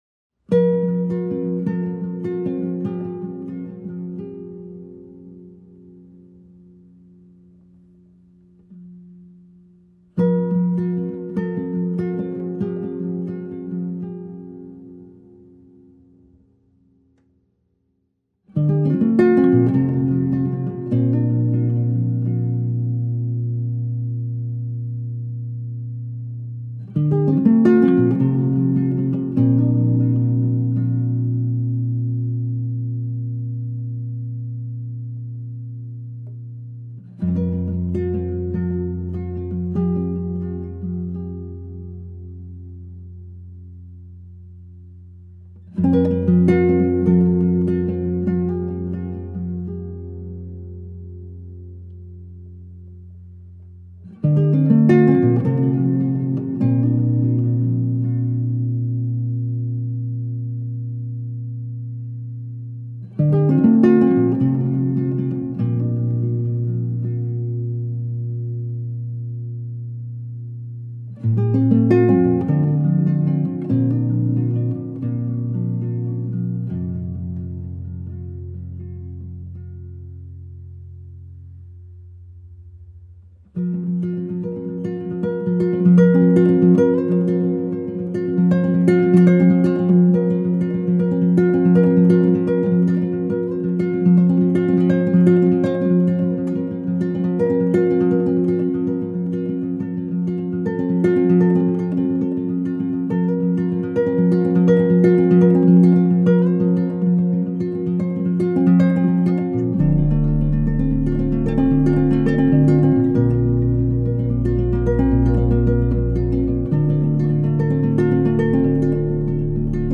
موسیقی کنار تو